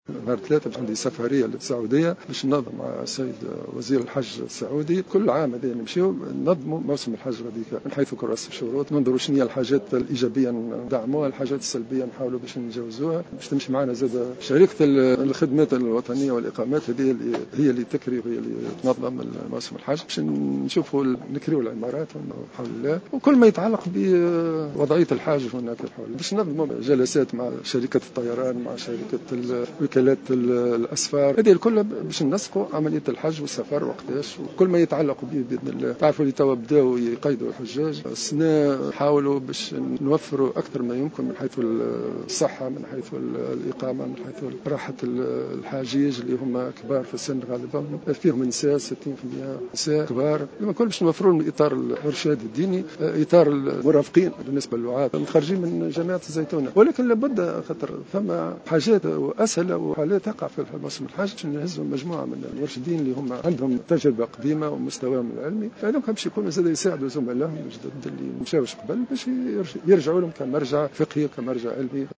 قال وزير الشؤون الدينية عثمان بطيخ في تصريح لجوهرة "اف ام" اليوم الإثنين 16 فيفري إنه سيؤدي غدا زيارة الى السعودية ليتفق مع وزير الحج السعودي بخصوص كراس شروط موسم الحج لهذه السنة ولتنظيم هذا الموسم على حد قوله.